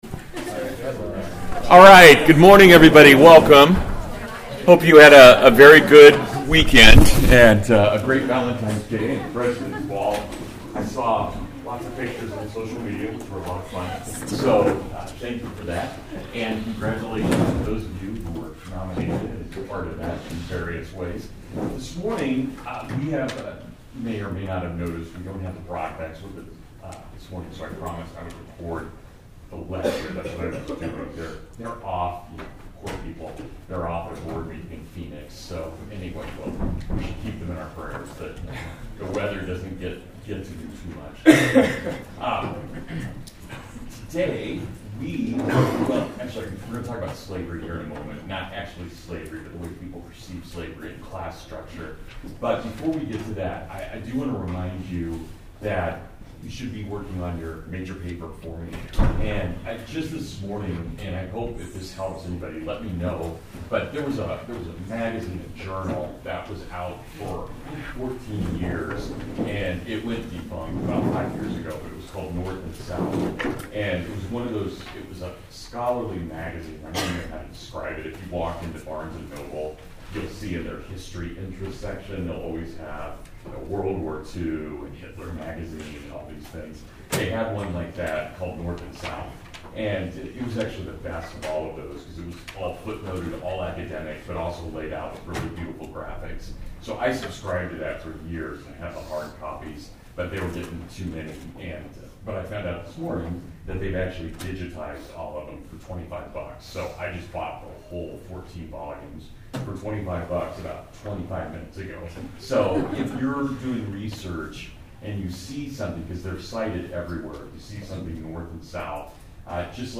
A lecture examining the conflicting ideas of the Free Soiler (North) and the Socialist (South).